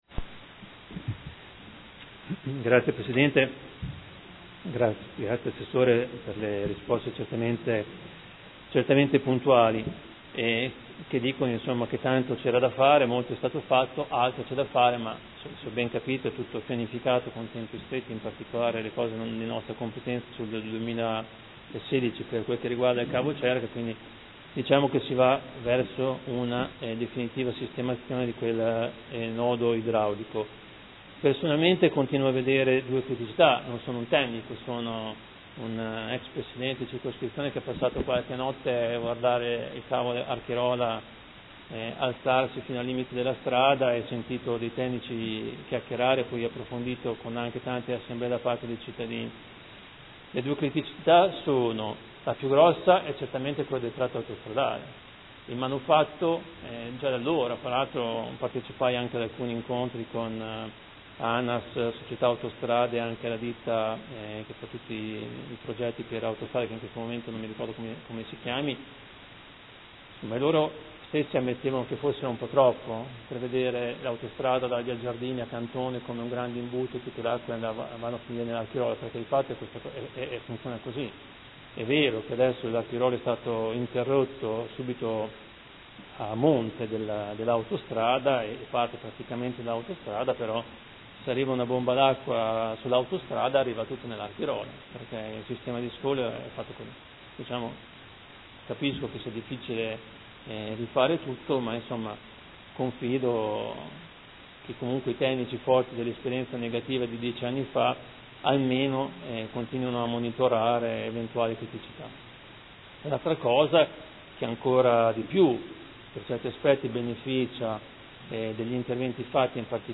Seduta del 29/10/2015 Replica. Interrogazione del Consigliere Poggi (P.D.) avente per oggetto: A dieci anni dall’esondazione del Cavo Archirola, qual è lo stato del nodo idraulico a sud di Modena